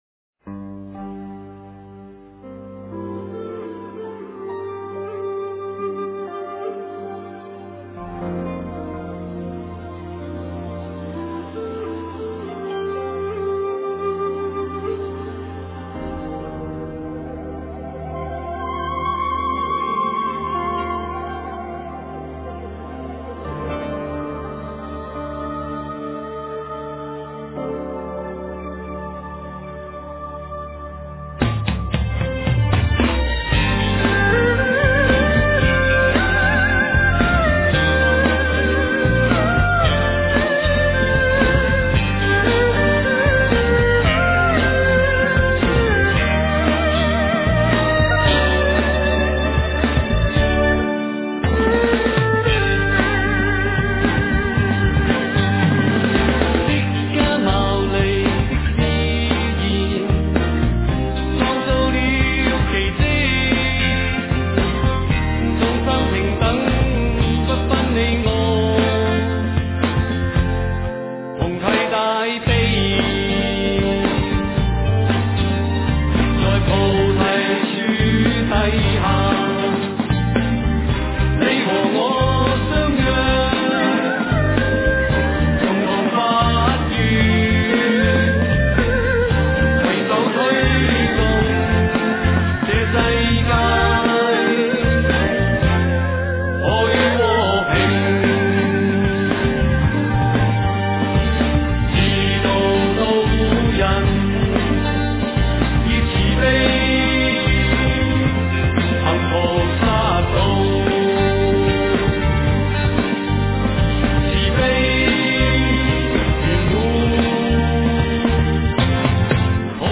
佛音
佛教音乐